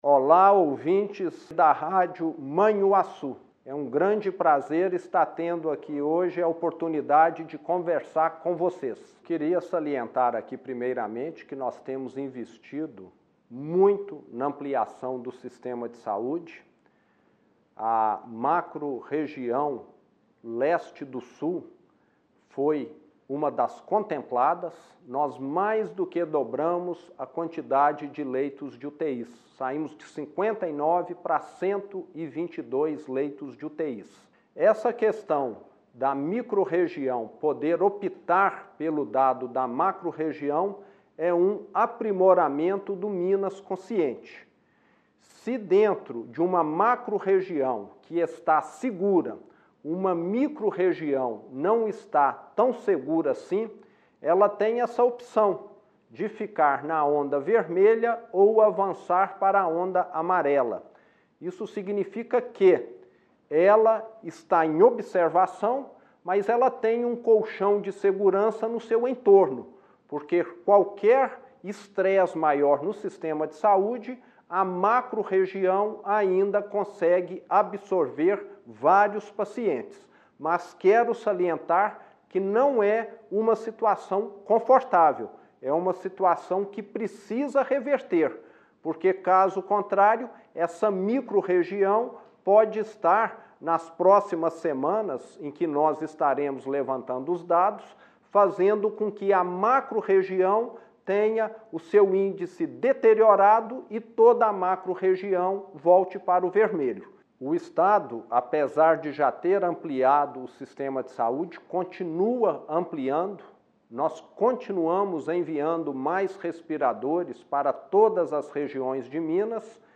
Em entrevista exclusiva a Rádio Manhuaçu e Jornal Tribuna do Leste, o governador Romeu Zema diz que a possibilidade de opção entre macro e microrregião é importante que seja feita com cautela pelo município.